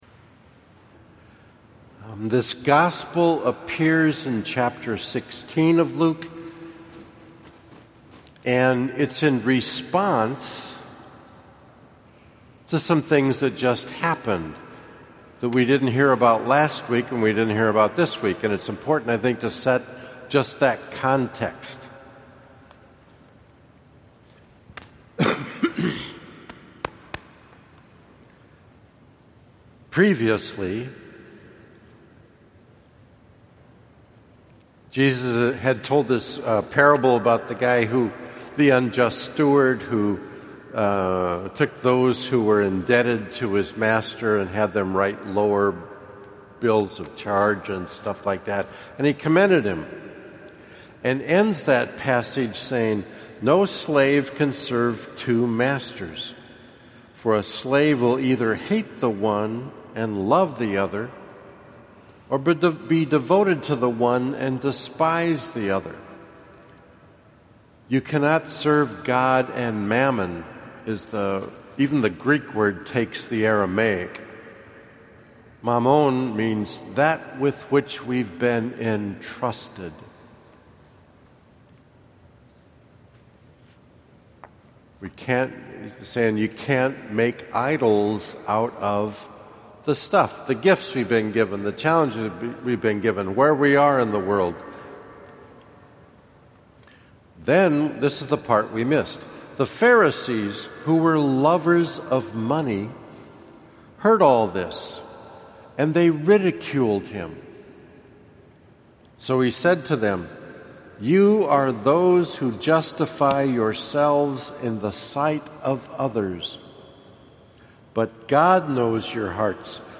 Audio version of homily for the 26th Sunday Ordinary Time: